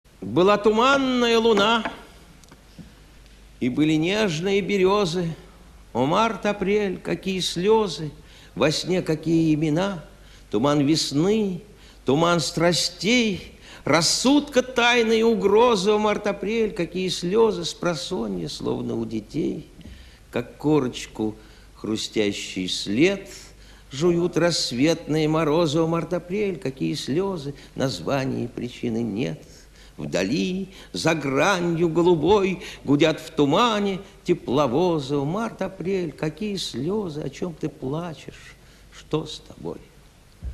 Samoylov-Byla-tumannaya-luna.-chitaet-avtor-stih-club-ru.mp3